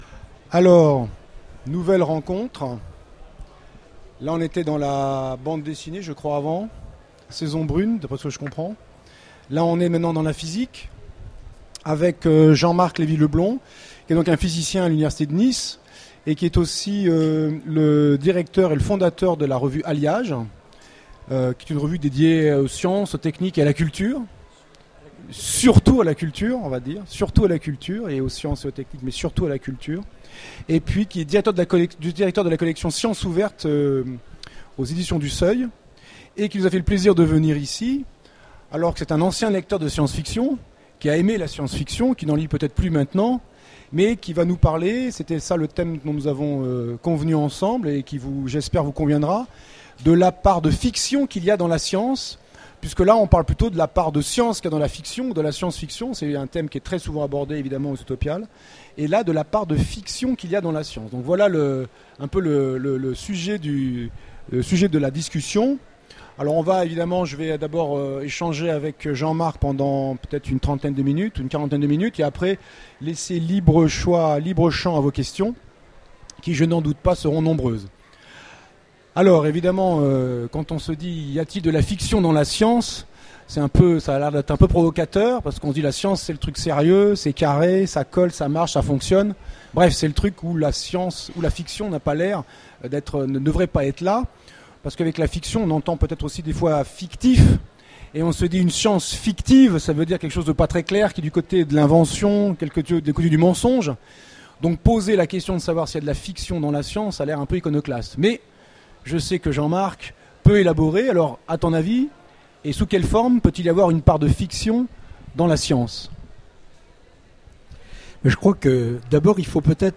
Utopiales 13 : Conférence Rencontre avec Jean-Marc Lévy-Leblond